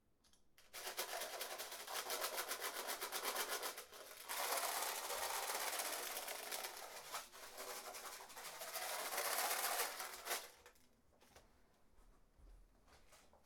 Zunächst haben wir die Aufnahme über die XLR-Eingänge getestet, einmal mit dem Tascam DR-100 MK2 und zum Vergleich mit dem Roland R-26.
Tascam DR-100 MK2
Die Aufnahmen sind hier im komprimierten MP3-Format mit 320 kb/s eingebettet – als guter Kompromiss aus Klangqualität und schnellen Ladezeiten für alle.